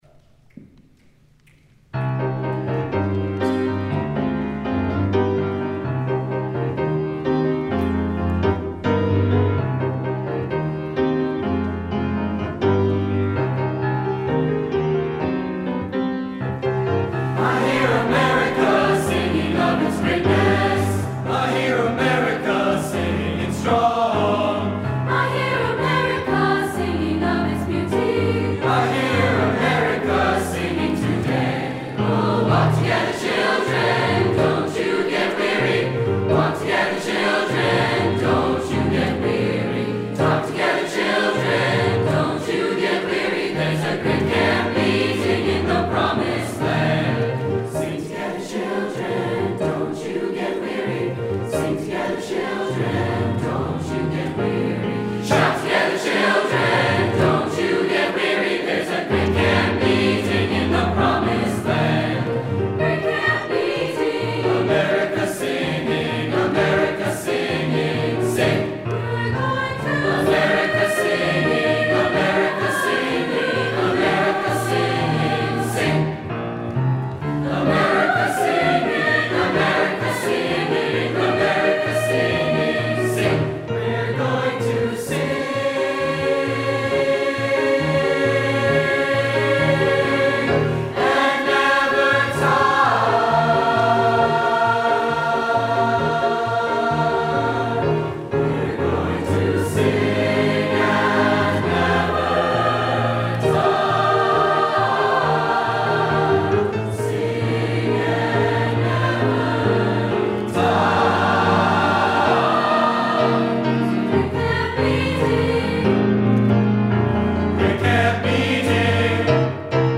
2018 Charter Oak Music Festival
Mixed Choir